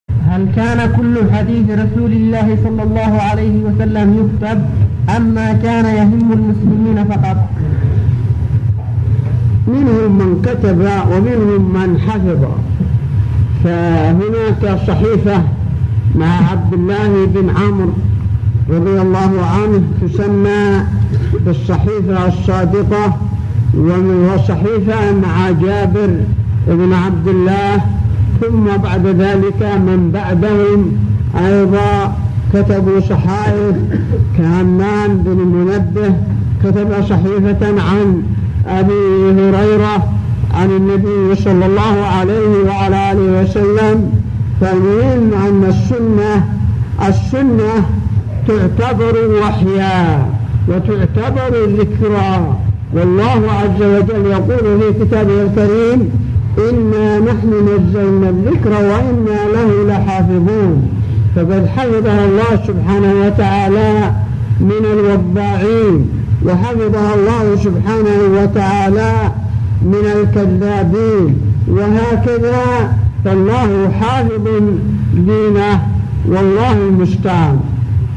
هل كان كل حديث رسول الله يكتب | فتاوى الشيخ مقبل بن هادي الوادعي رحمه الله
--------------- من شريط : ( أسئلة شباب قرية السعيد )